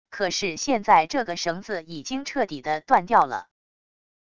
可是现在这个绳子已经彻底的断掉了wav音频生成系统WAV Audio Player